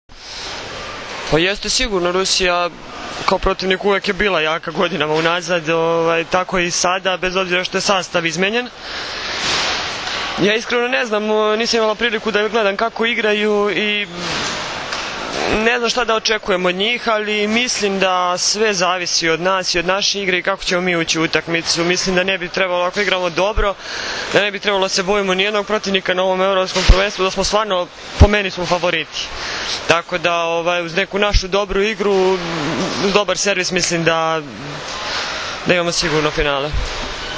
IZJAVA NATAŠE KRSMANOVIĆ